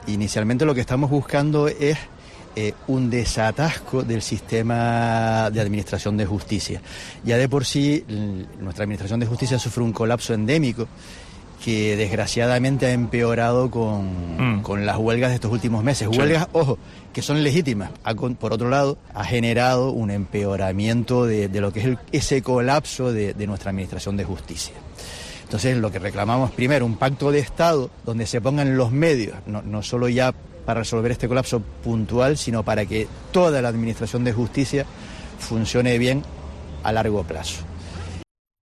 En estos términos se ha manifestado hoy en La Mañana de COPE Tenerife uno de los portavoces del colectivo